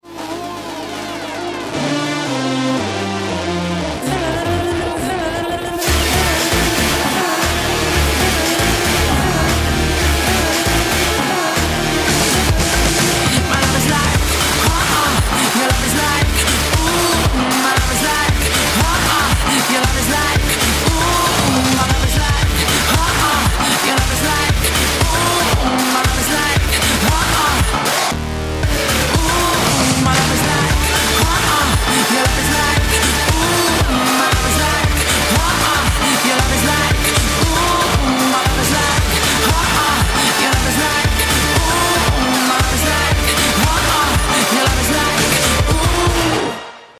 • Качество: 128, Stereo
женский вокал
веселые
dance
club
рок